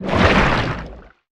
Sfx_creature_spikeytrap_letgo_01.ogg